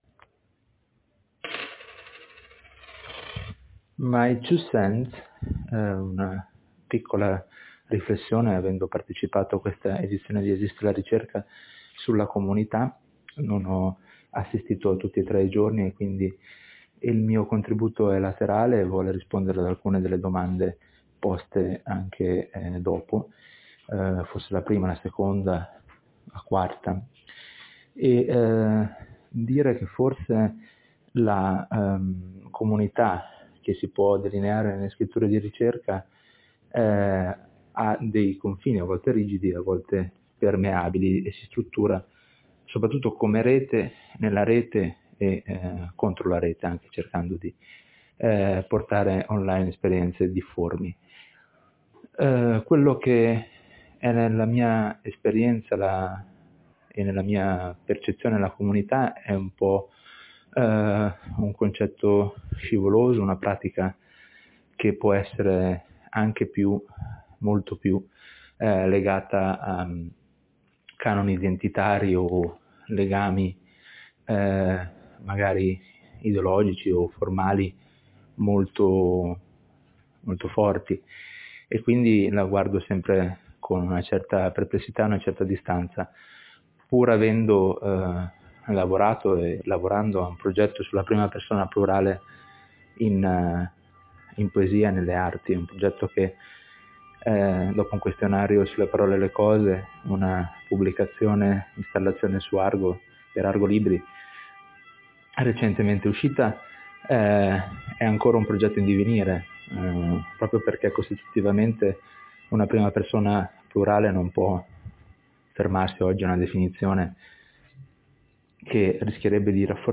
Come in occasione di uno dei due incontri milanesi del 2023, anche stavolta una microintervista composta da 4 domande fisse viene proposta a diversi interlocutori del (o partecipanti al) quinto momento, 2025, di Esiste la ricerca, dedicato al tema delle comunità e dei progetti letterari.
È lasciata la massima libertà nel rispondere (o non rispondere) via mp3, ma è richiesta una serie di condizioni in linea con la natura estemporanea di ELR: i vocali non devono essere ‘preparati’, né letti, né (poi) editati, né in sostanza pensati come elementi di un’esposizione calligrafica / accademica, bensì pronunciati al microfono e registrati così come nascono.